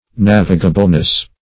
navigableness - definition of navigableness - synonyms, pronunciation, spelling from Free Dictionary
[1913 Webster] -- Nav"i*ga*ble*ness, n. --